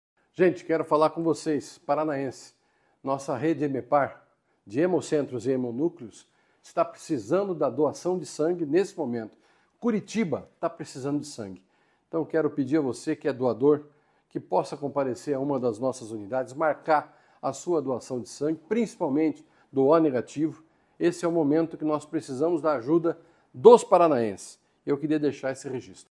Sonora do secretário da Saúde, Beto Preto, sobre a necessidade do Hemepar Curitiba de doações de sangue com urgência